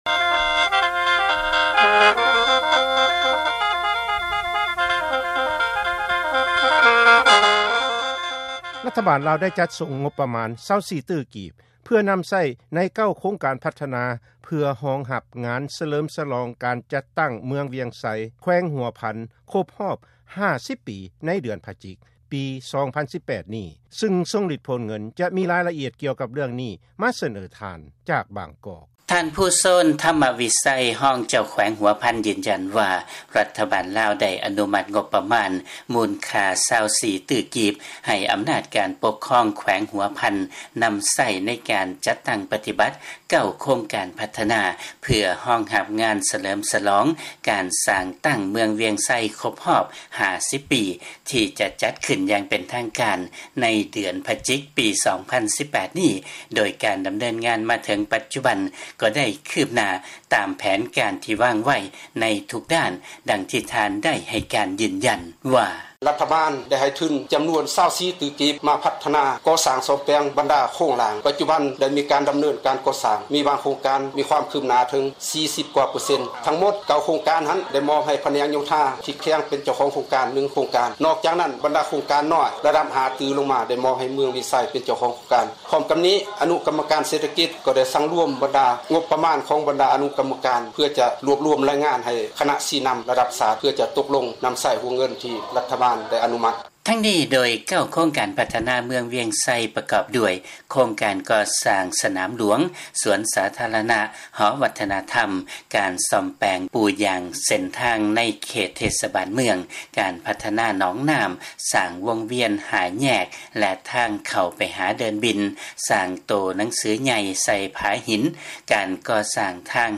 ມີລາຍງານຈາກບາງກອກ.